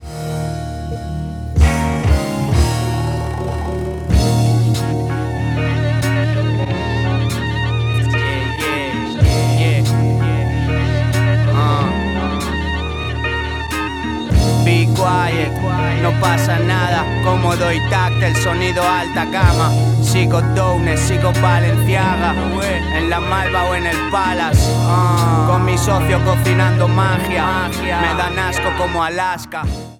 Hip Hop/Dj Tools